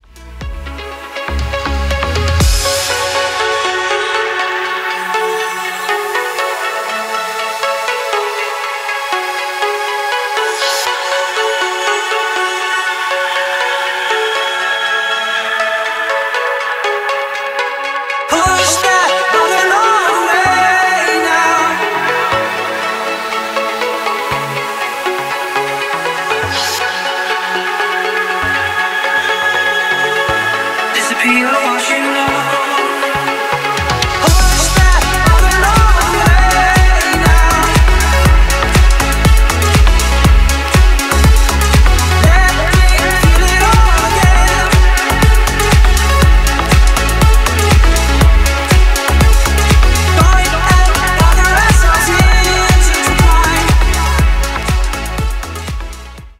• Качество: 320, Stereo
Стиль: deep house.